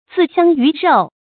自相魚肉 注音： ㄗㄧˋ ㄒㄧㄤ ㄧㄩˊ ㄖㄡˋ 讀音讀法： 意思解釋： 魚肉：以人為魚肉；比喻殘殺。